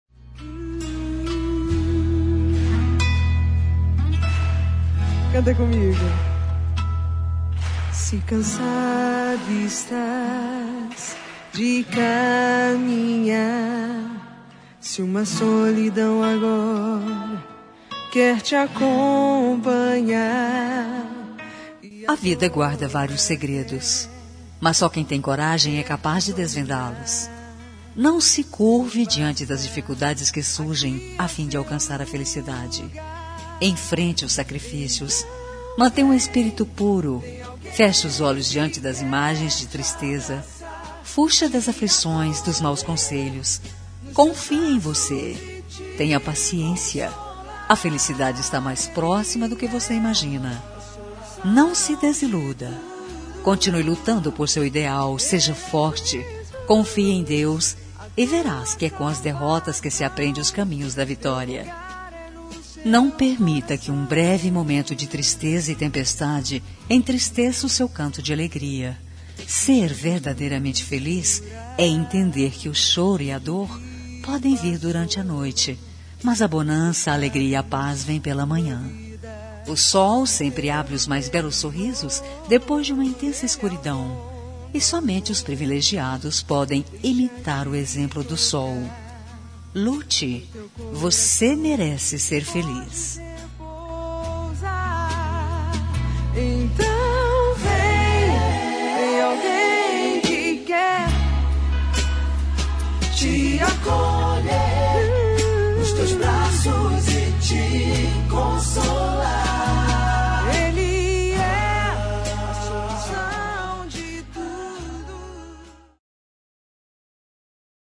Telemensagem de Otimismo – Voz Feminina – Cód: 190